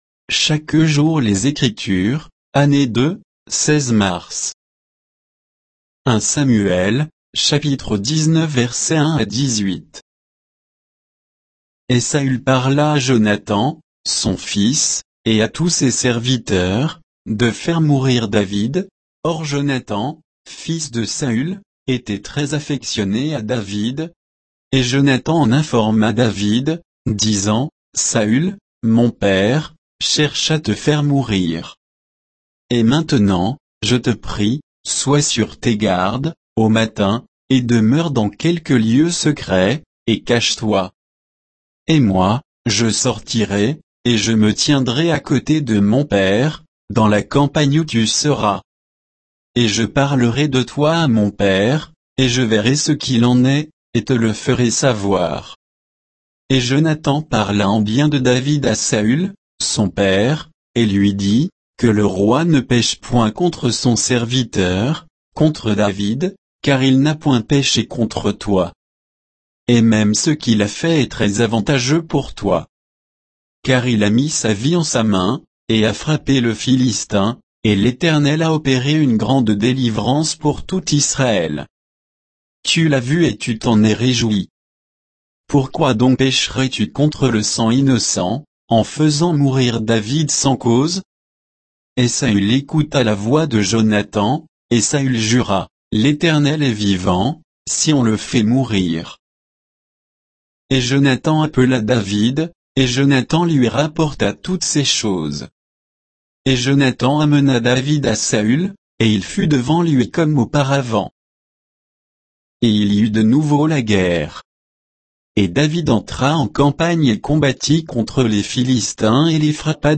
Méditation quoditienne de Chaque jour les Écritures sur 1 Samuel 19